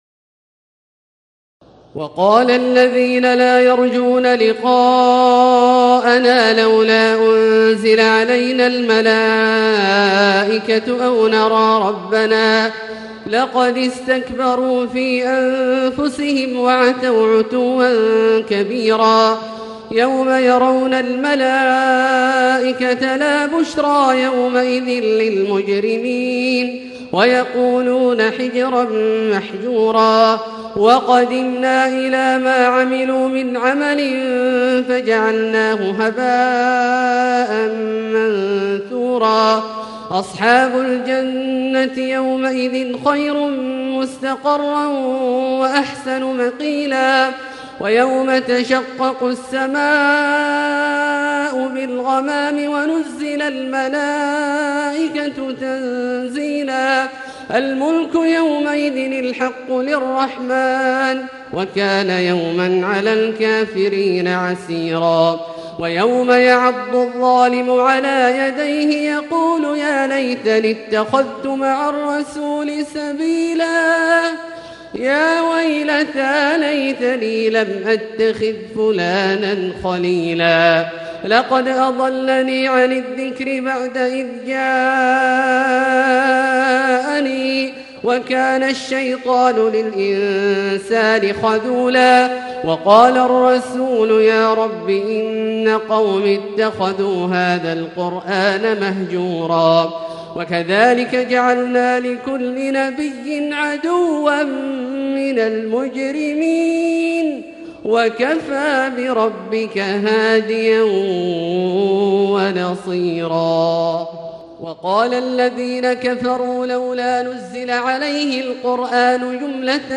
تراويح الليلة الثامنة عشر رمضان 1440هـ من سورتي الفرقان (21-77) و الشعراء (1-104) Taraweeh 18 st night Ramadan 1440H from Surah Al-Furqaan and Ash-Shu'araa > تراويح الحرم المكي عام 1440 🕋 > التراويح - تلاوات الحرمين